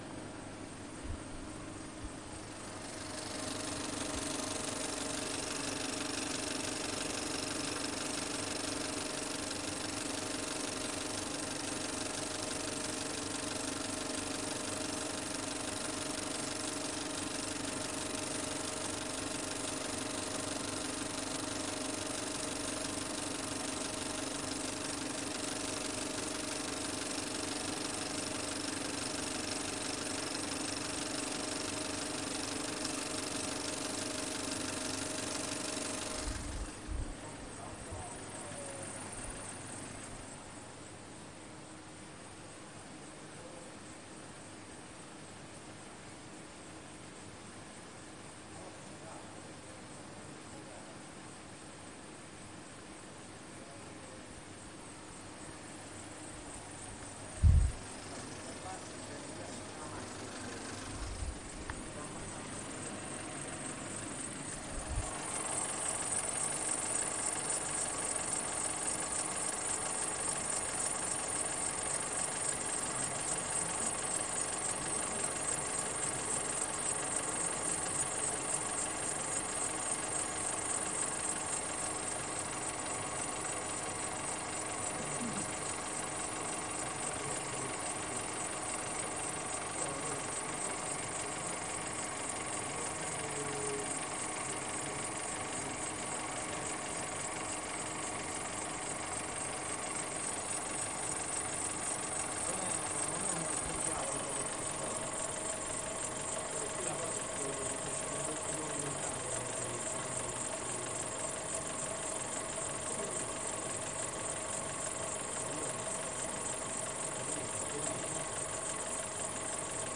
电影放映机04 - 声音 - 淘声网 - 免费音效素材资源|视频游戏配乐下载
sennheiser mkh 416声音设备744t